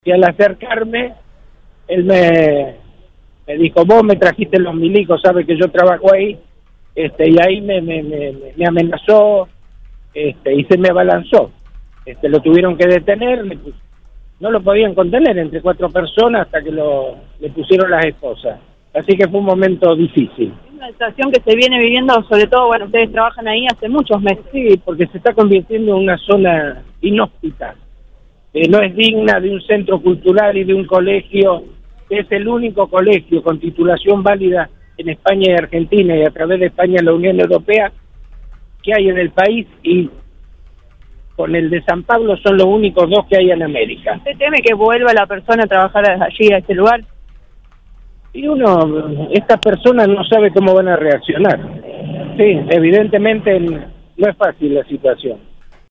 “Fui agredido por el cuidacoche, me dijo que le había llevado los milicos, sabe que trabajo ahí. Me amenazó, se me abalanzó, no lo podían contener hasta que le pusieron las esposas. Fue un momento difícil”, relató al móvil de Cadena 3 Rosario en Viva la Radio.